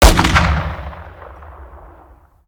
weap_br2_fire_plr_atmo_ext1_07.ogg